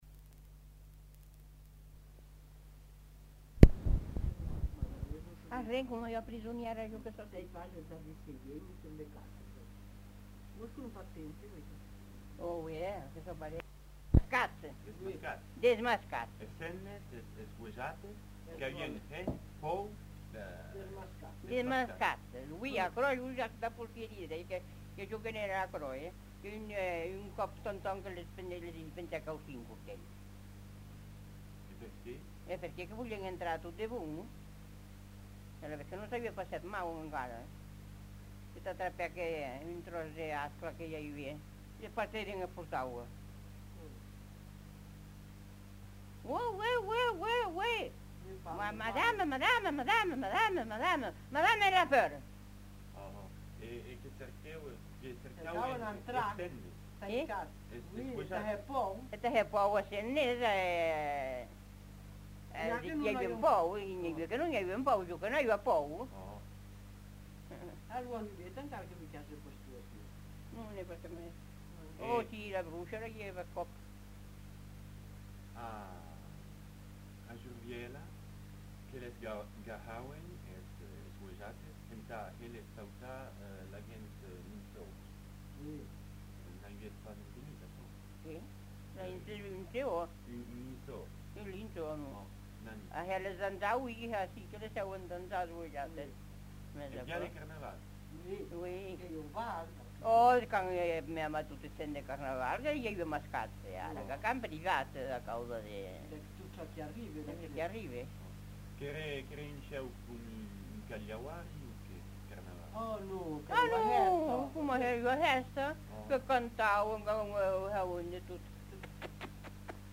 Aire culturelle : Comminges
Lieu : Gouaux-de-Larboust
Genre : témoignage thématique